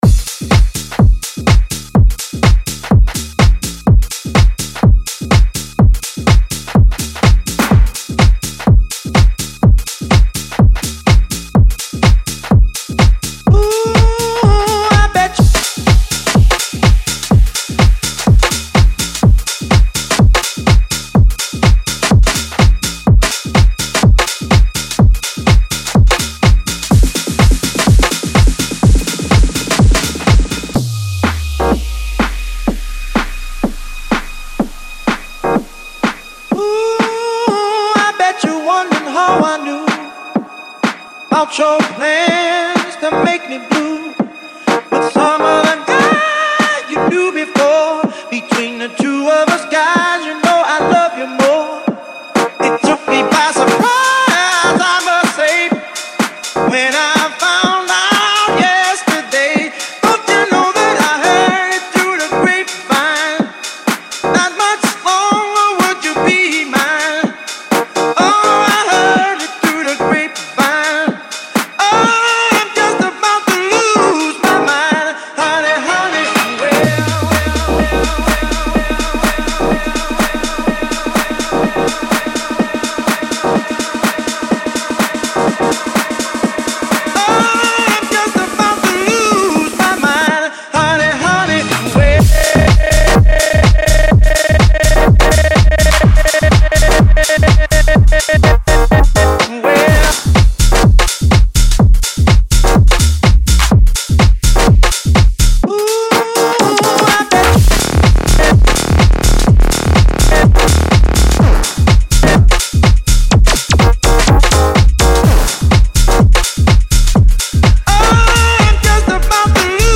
Genre: Bassline , Garage , House